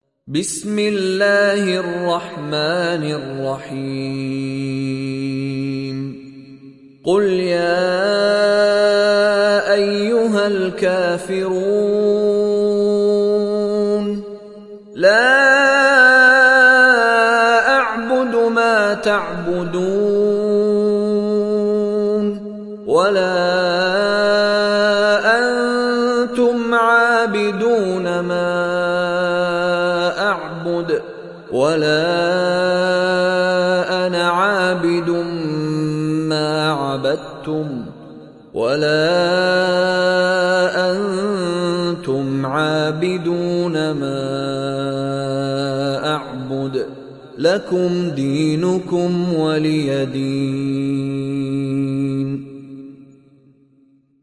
تحميل سورة الكافرون mp3 بصوت مشاري راشد العفاسي برواية حفص عن عاصم, تحميل استماع القرآن الكريم على الجوال mp3 كاملا بروابط مباشرة وسريعة